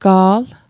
Pronunciation Guide: gaal